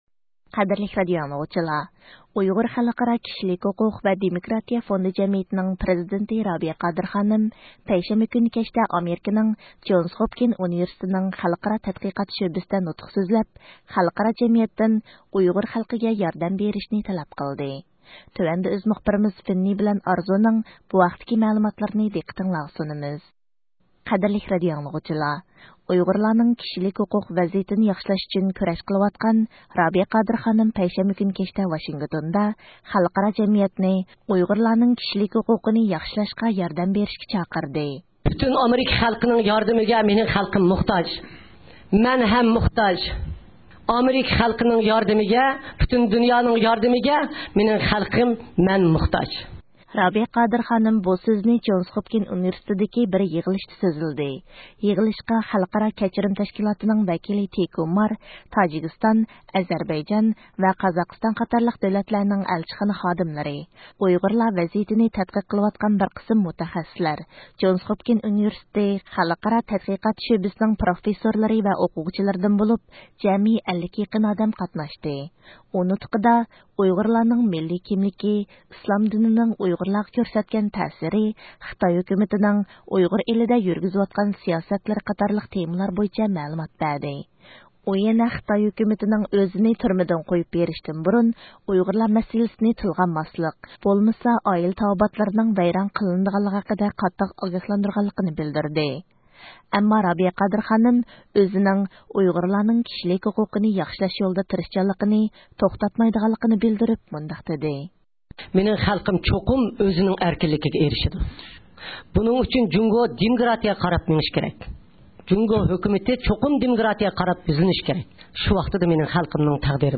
رابىيە قادىر خانىم بۇ سۆزنى جونىس خوپكىنىس ئۇنىۋېرسىتېتىدىكى بىر يىغىلىشتا سۆزلىدى.